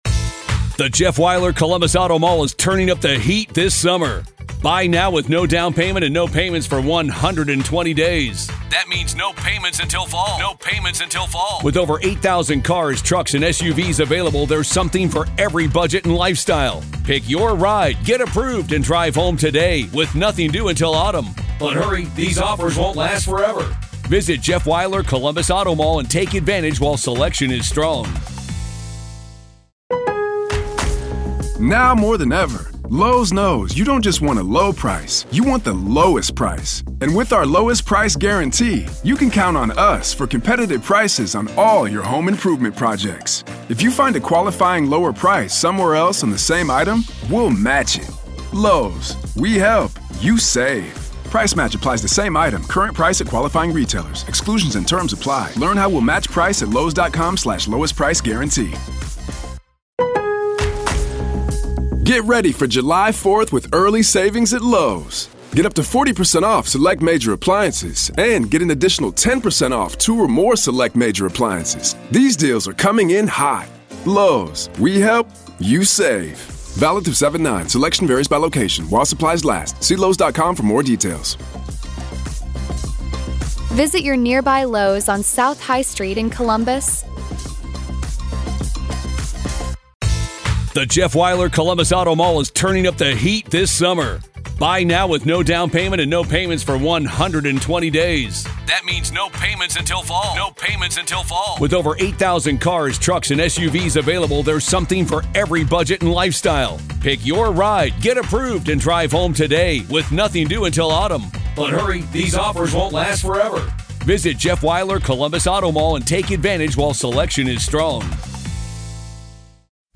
DEFENSE CLOSING ARGUMENTS PART 2: The Trial of Karen Read: Boyfriend Cop Murder Trial – MA v. Karen Read
Welcome to a special episode of "The Trial of Karen Read," where today, we find ourselves inside the courtroom of the case against Karen Read.